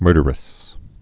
(mûrdər-ĭs)